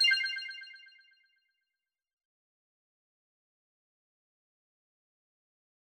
confirm_style_4_echo_007.wav